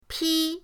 pi1.mp3